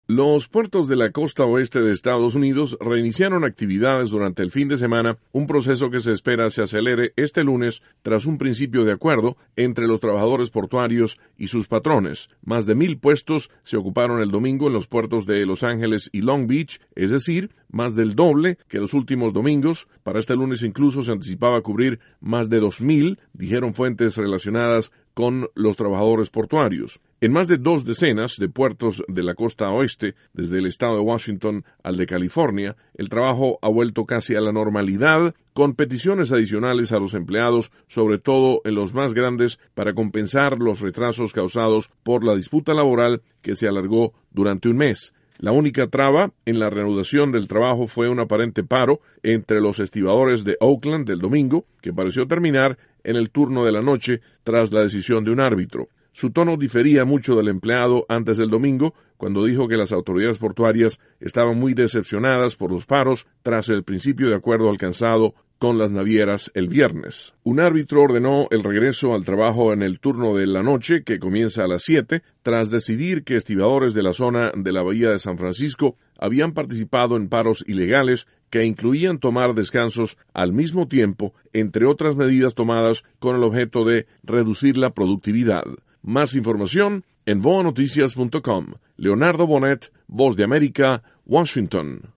informa desde Washington.